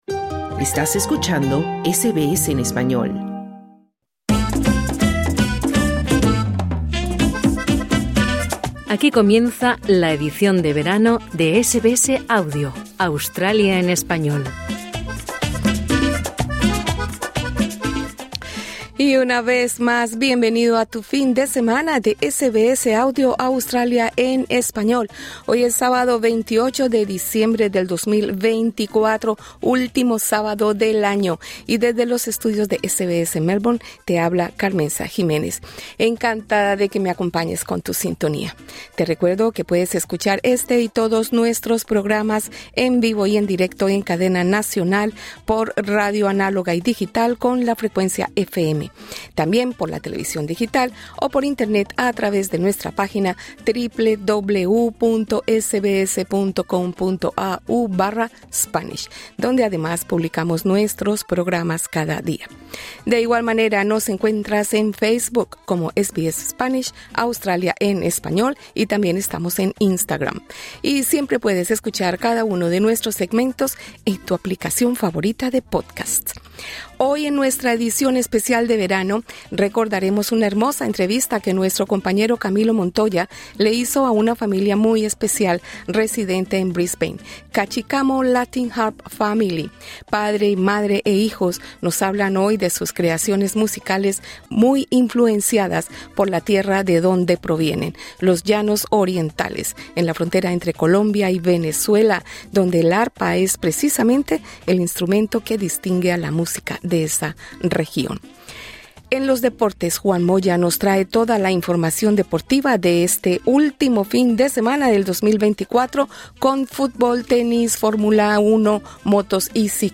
Hoy en nuestra edición especial de verano, recordamos la entrevista a una familia muy especial, residente en Brisbane: Cachicamo Latin Harp Family. Padre, madre e hijos nos hablan de sus creaciones musicales muy influenciadas por la tierra de donde provienen: los llanos orientales, en la frontera entre Colombia y Venezuela, donde el arpa es precisamente el instrumento que distingue la música de esa región.